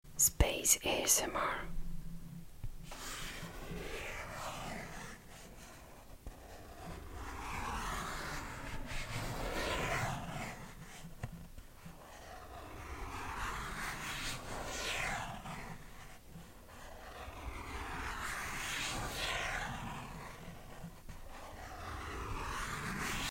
Drift into space with relaxing sound effects free download
Drift into space with relaxing cosmic ASMR… No talking, just pure intergalactic tingles.